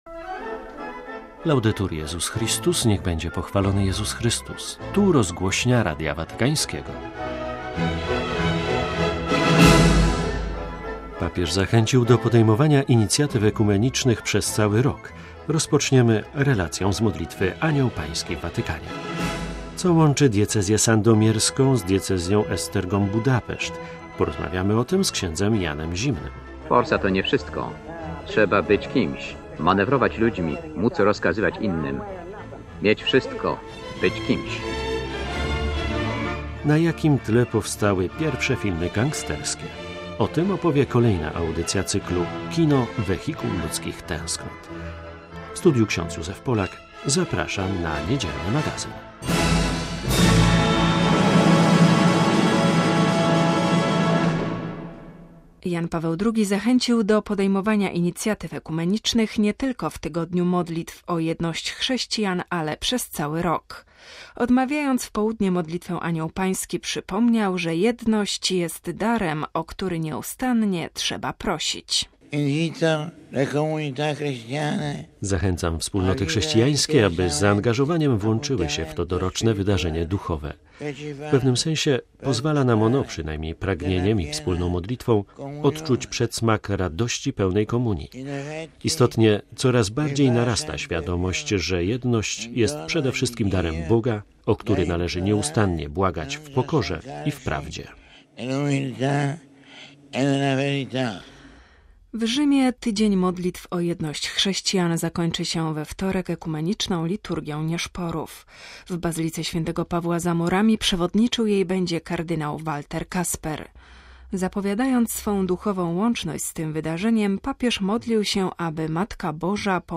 Papież zachęcił do podejmowania inicjatyw ekumenicznych nie tylko w Tygodniu Modlitw o Jedność chrześcijan, ale przez cały rok. Rozpoczniemy relacją z modlitwy Anioł Pański w Watykanie.